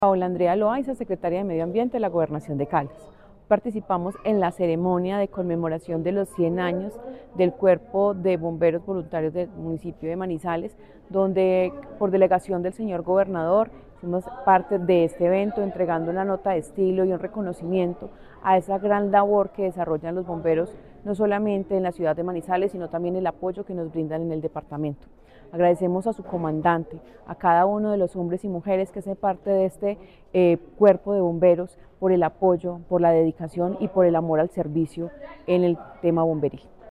La Secretaría de Medio Ambiente de Caldas y su Jefatura de Gestión del Riesgo, participó en la ceremonia conmemorativa por los 100 años del Benemérito Cuerpo de Bomberos Voluntarios de Manizales, una de las instituciones emblemáticas del departamento por su compromiso con la atención de emergencias y la protección de la vida.
Paola Andrea Loaiza Cruz, secretaria de Medio Ambiente de Caldas